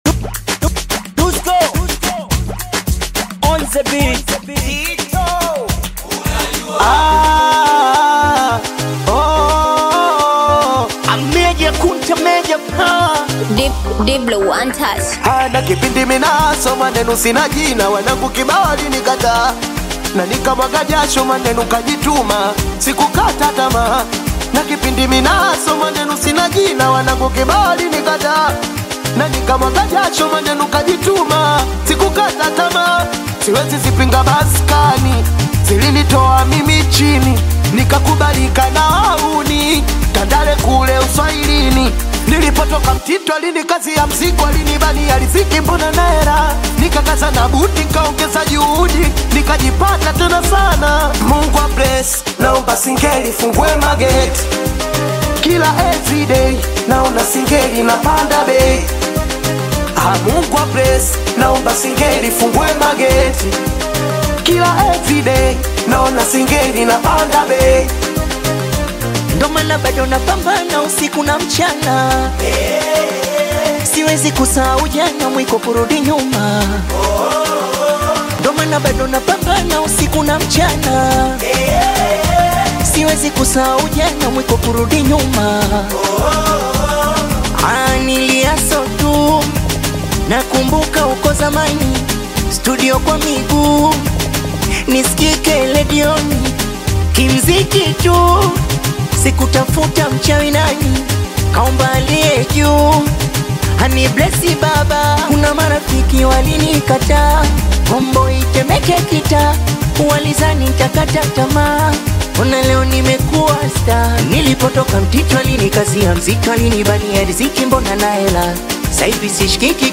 Bongo Flava
Singeli You may also like